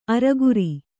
♪ araguri